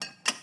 SFX_Glass_02.wav